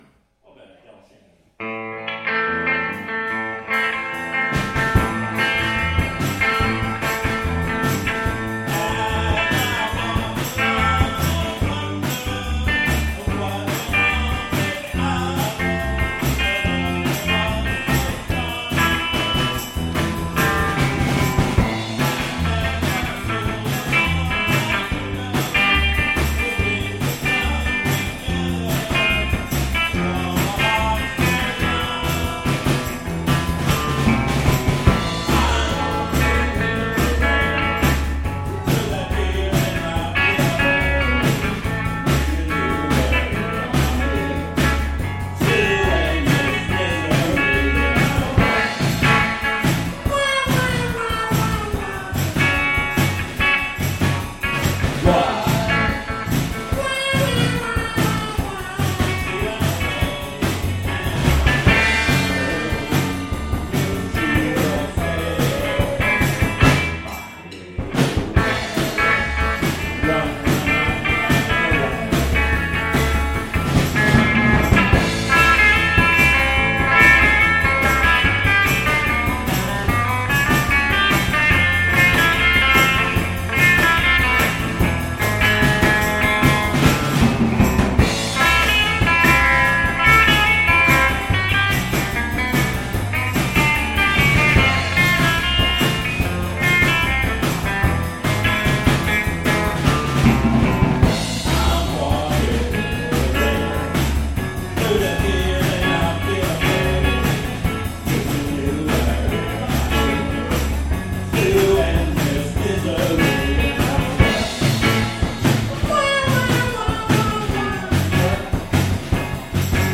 with myself on guitar and vocals
drums
bass